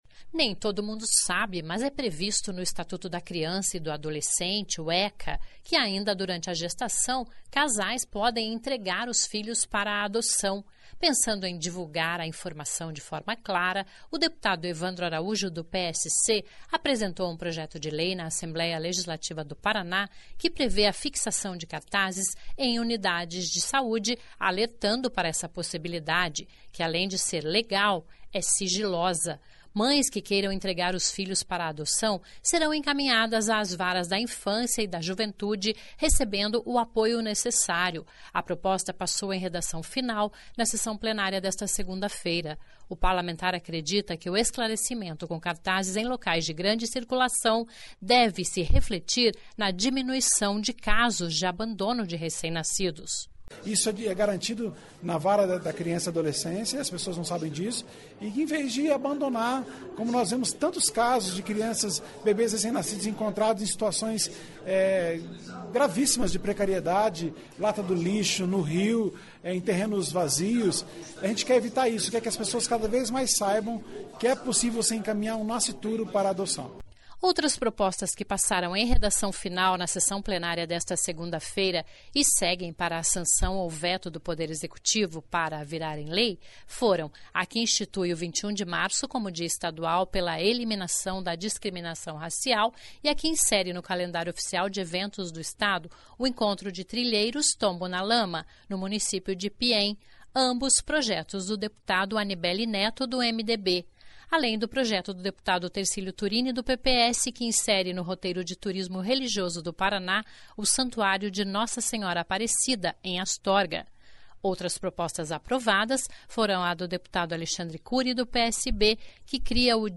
(Sonora Evandro Araújo))